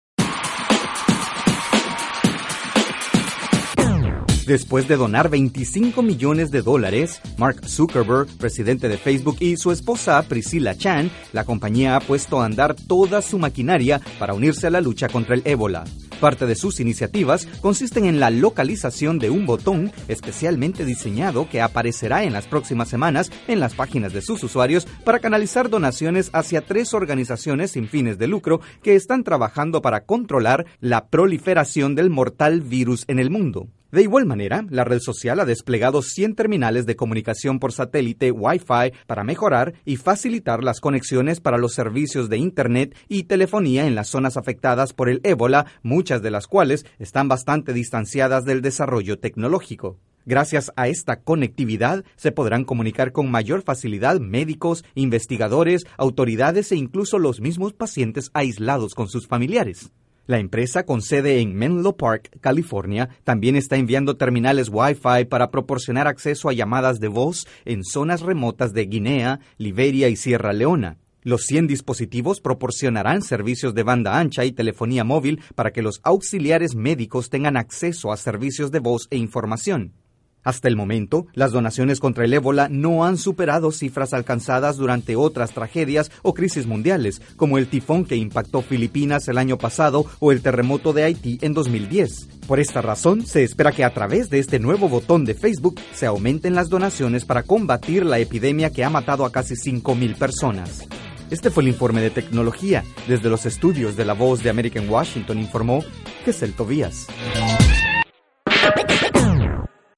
La red social crea un botón que aparecerá en las páginas de sus usuarios para facilitar donaciones a organizaciones que luchan por combatir la enfermedad. Desde los estudios de la Voz de América informa